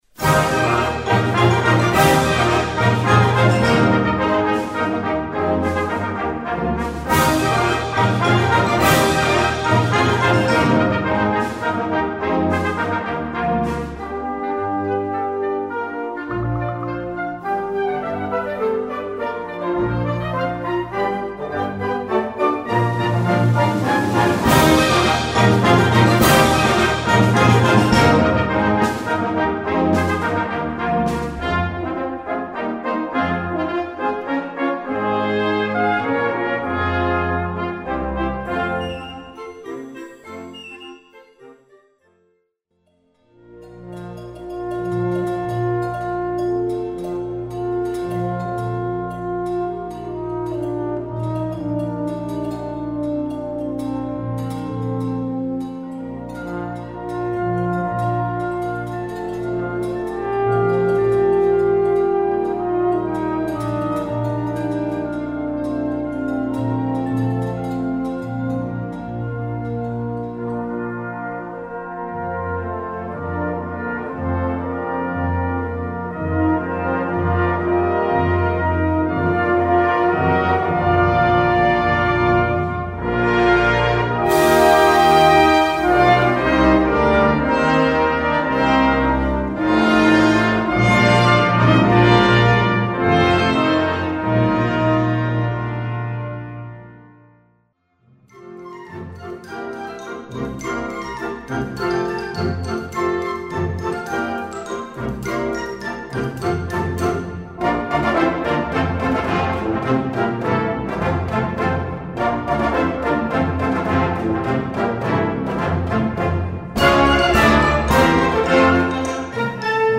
Catégorie Harmonie/Fanfare/Brass-band
Sous-catégorie Pot-pourri, medley : musique traditionnelle
Instrumentation Ha (orchestre d'harmonie)